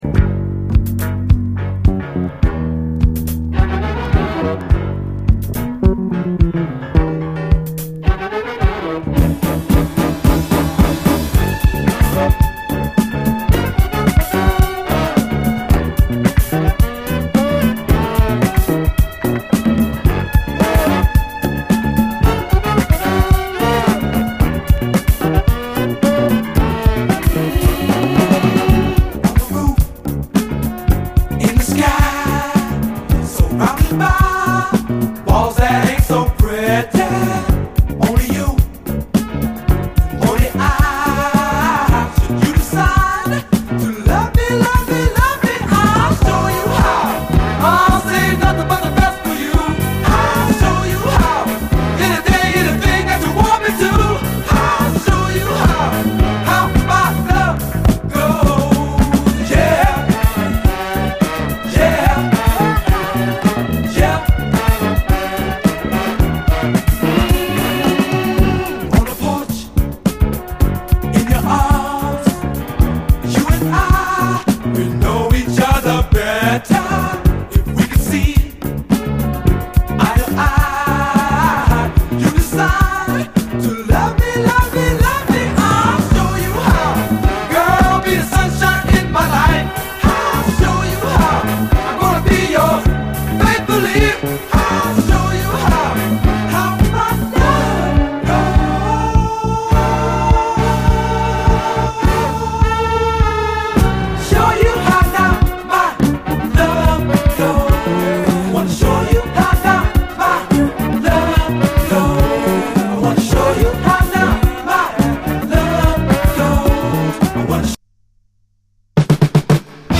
様々なディスコ・ヒットの弾き直しトラックによるB級カヴァーやディスコ・ラップ群が嬉しい！
どちらも後半にラップ入り